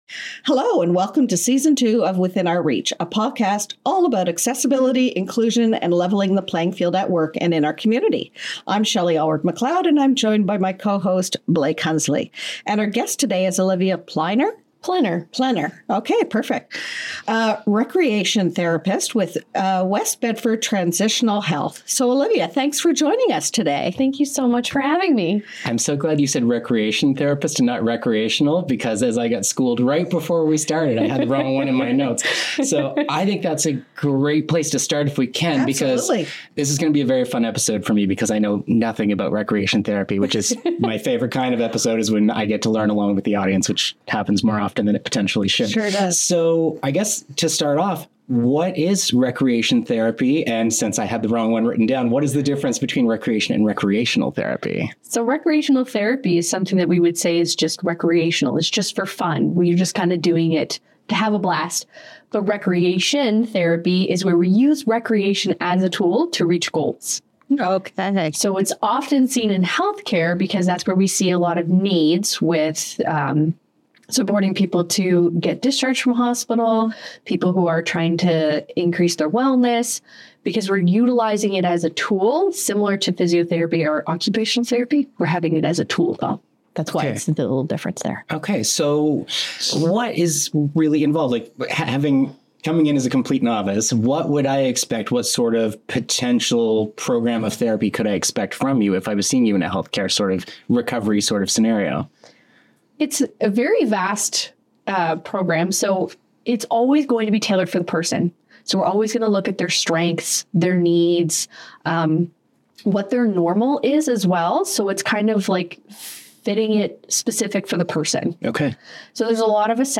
This interview was recorded during Recreation Therapy Month (February).